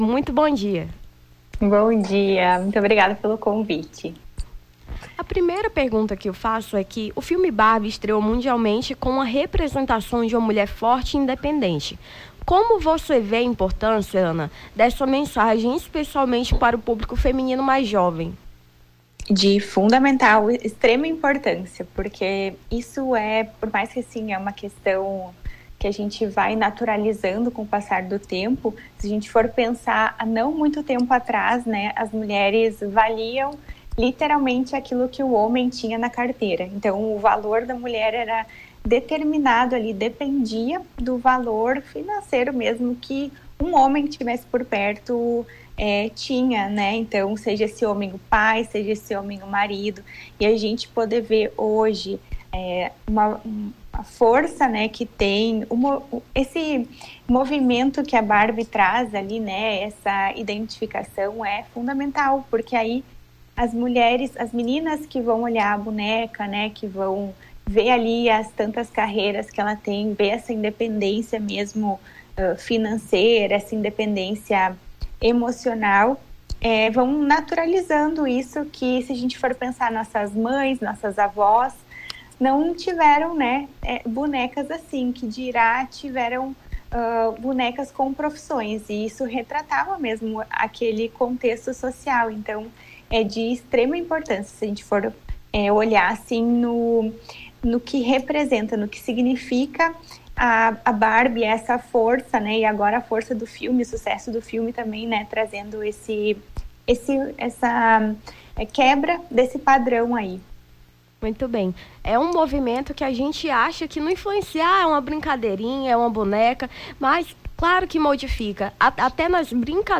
Nome do Artista - CENSURA - ENTREVISTA (EMPODERAMENTO MULHERES BARBIE) 24-07-23.mp3